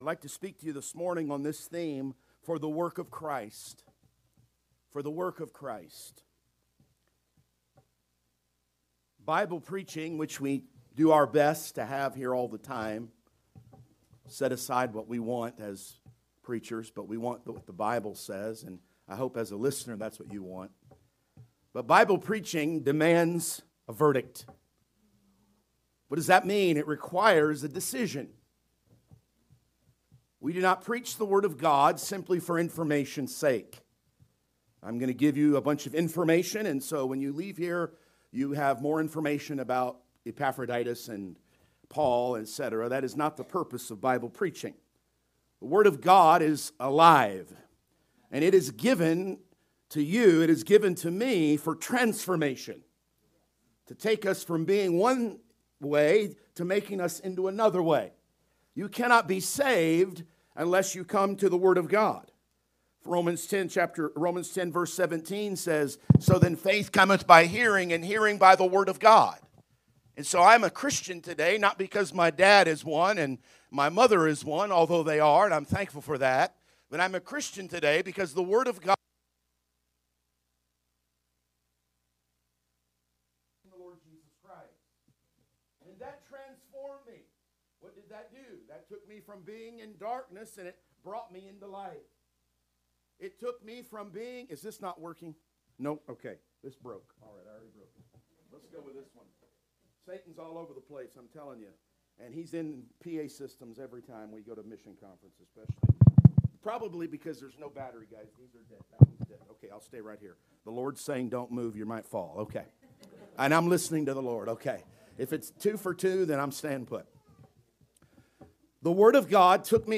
Sermons | Anchor Baptist Church
Mission Conference 2025 - Sunday Morning